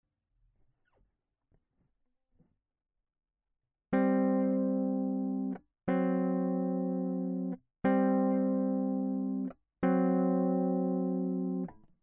E fino a qua, nulla di strano, ma la prima cosa da imparare e vedere l'accordo suonando il voicing solo con la mano DX. nel caso specifico i due accordi in esame, proviamo quindi a suonare i voicing in questione solo con l'utilizzo della mano Dx. come nell'ex.2 e facendo riferimento alla Fig.3) e Fig.4)